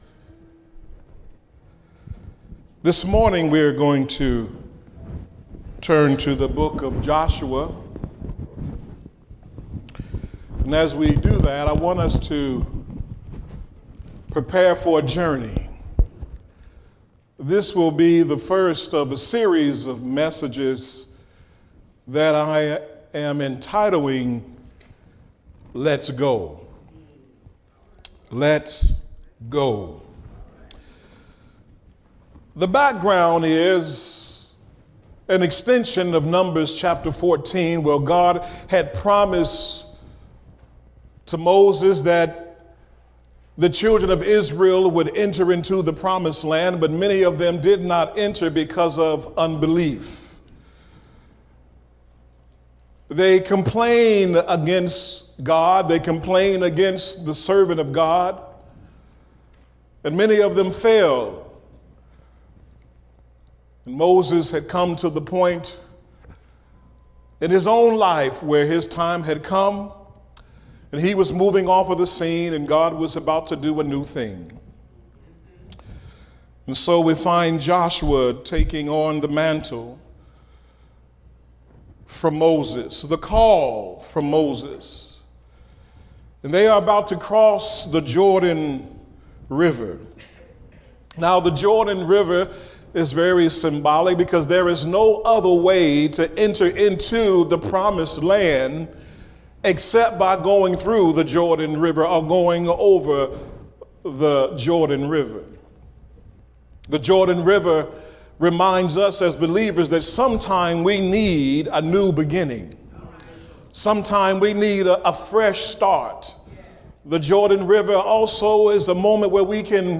Genre: Spoken Word.